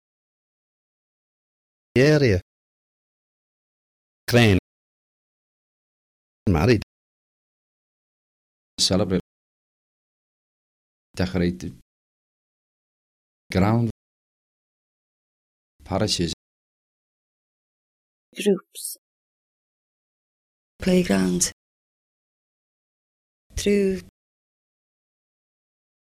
The final example from Liverpool illustrates apical taps or trills from Liverpool informants (in contrast there were no taps or trills from any of the other places).
Example 5: Liverpool tapped or trilled r:
00liverpooltrill.mp3